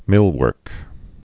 (mĭlwûrk)